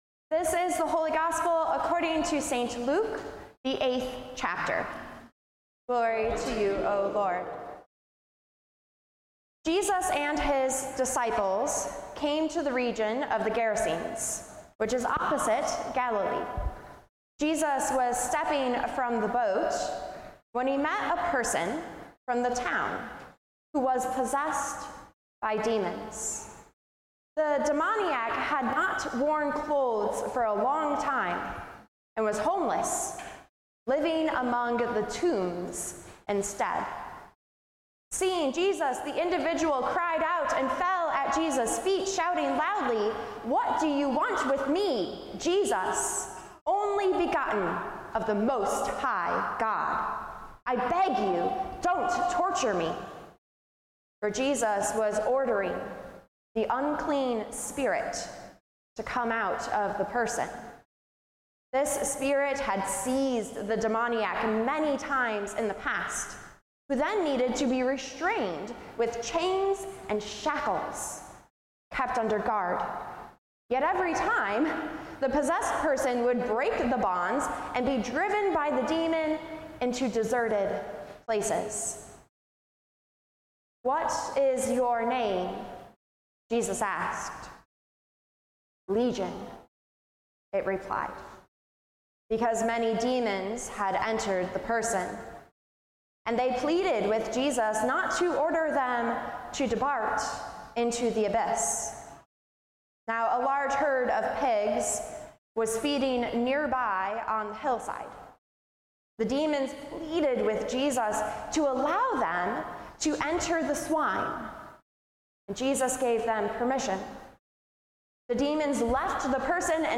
Sermons | Grace Evangelical Lutheran Church